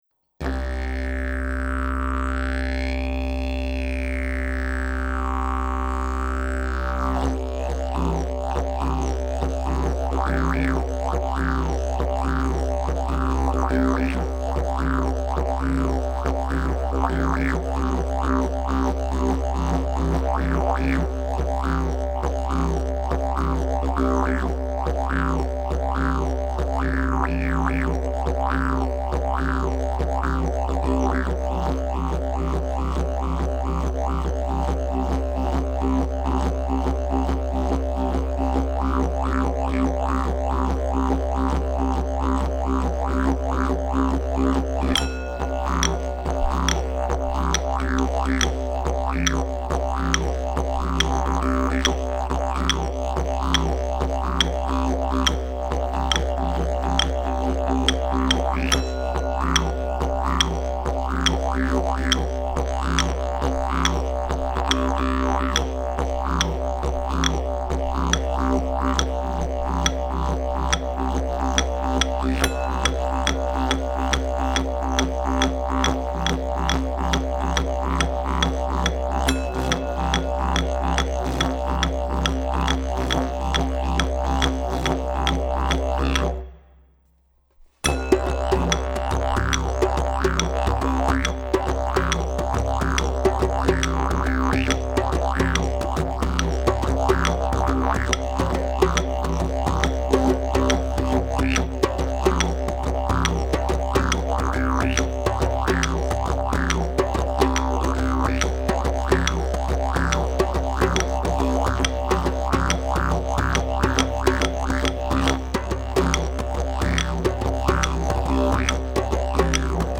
Du coup me suis venu une envie irrésistible de faire un morceau dans cet esprit, voilà, c'est sans prétention aucune, très simple techniquement, ça trace tout droit et pis voilà, juste pour le plaisir!! :happy1:
Ouais, en fait j'ai fait le didge, puis le reste, puis j'ai refait le didge pour que ce soit plus vivant.
carré comme d'hab, et les instruments arrivent juste au bon moment
j'adore, parce que c'est très entraînant
Très mélodique et agréable à écouter.
La ligne de didj reste trop simplexe et répétitive à mon gout même si c'est très bien exécuté.
Niveau prod, le son du didj est vraiment très beau, le mix a beaucoup d'air, on retrouve bien ta patte ce qui contribue à rende le morceau agréable à écouter.